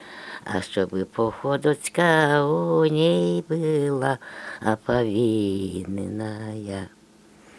Мягкое цоканье (совпадение литературных аффрикат /ц/ и /ч’/ в мягком /ц’/)
/а-т’и”-ха см’и”-рна в’е”-д’и ту”-ты р’е”-ц’и да”-кы л’ее-б’е-д’ии”-ны-ны-йа/